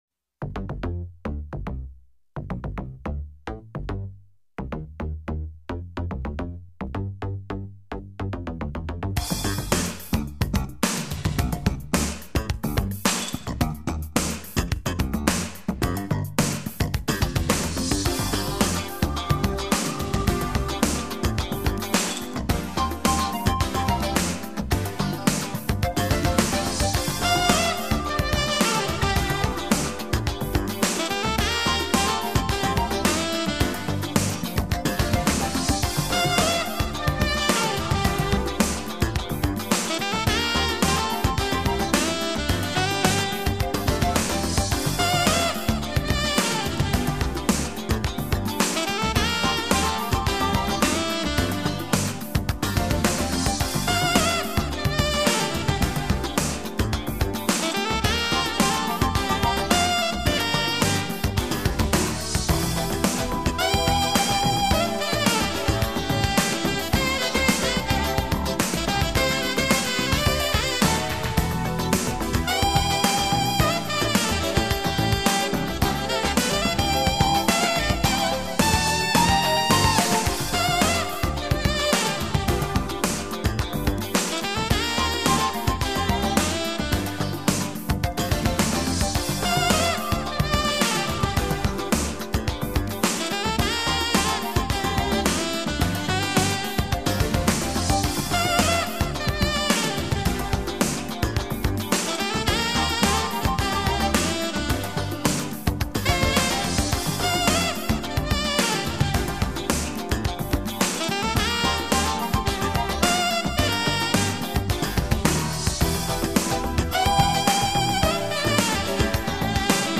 高超华丽的演奏技巧、多元抒情的曲风，这就是优雅、华丽而独特的“吉”式音乐。